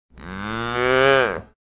cow_moo1.ogg